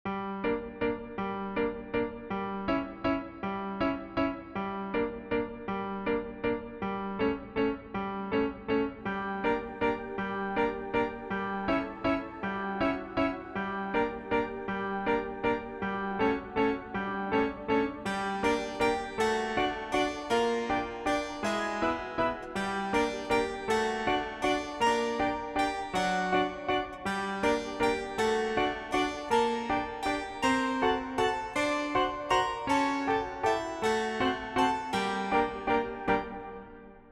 "Improved" Game Over loop + menu bgm